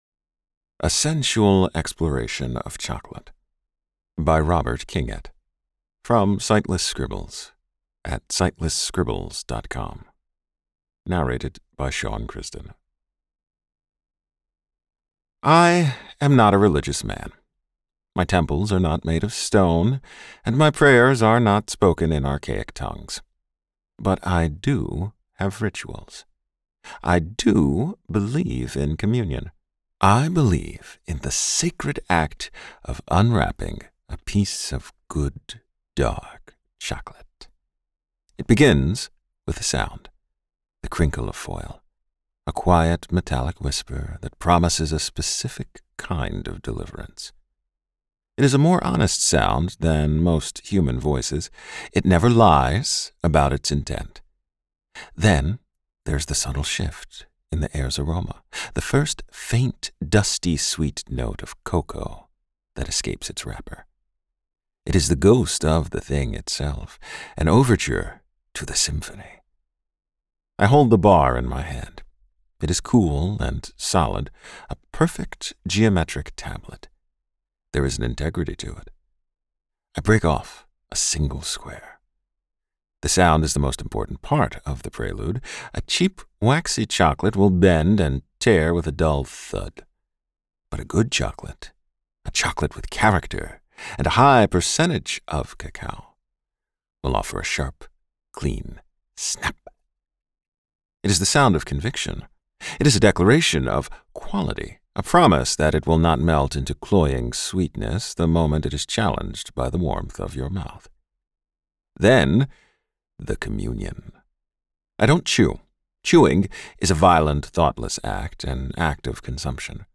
I am so thrilled to narrate some of the works of Mr. Kingett that it brings me immense joy.